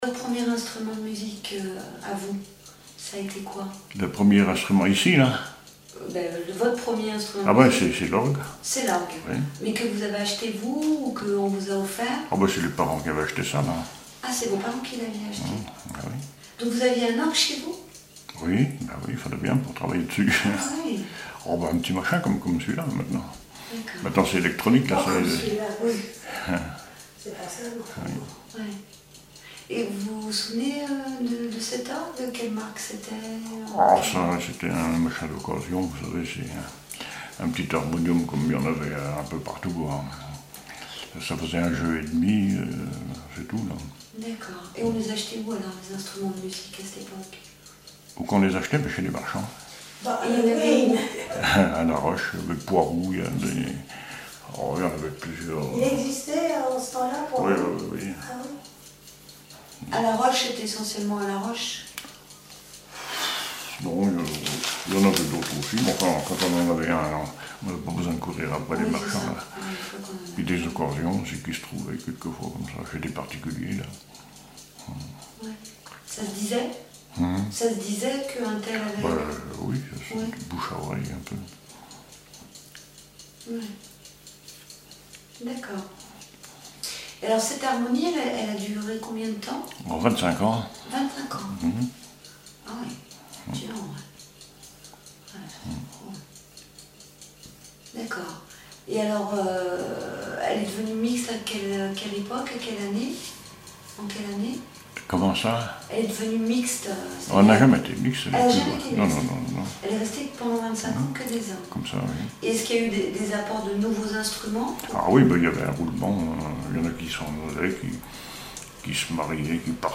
témoignages sur la musique et une chanson
Catégorie Témoignage